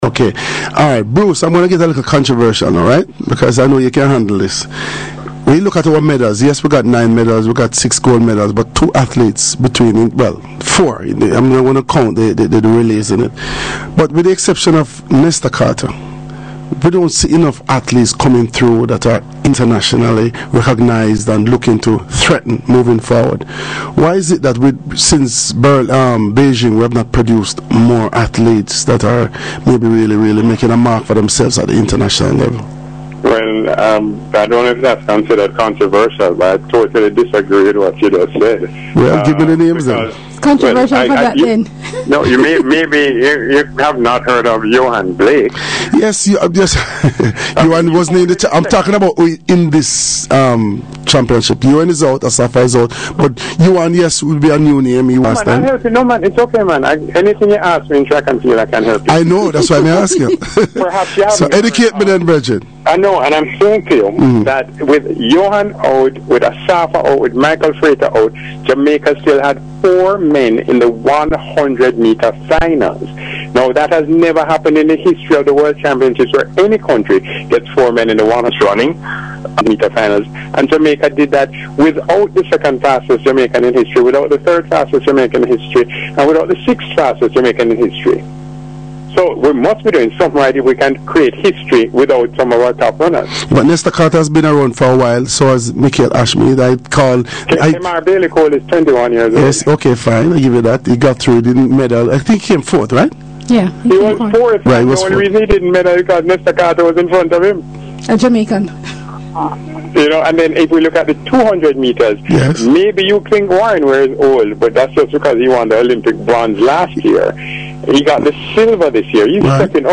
Here are the final snippets from my radio interview on Sportsline on Hot102FM in Jamaica last week Monday.